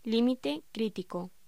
Locución: Límite crítico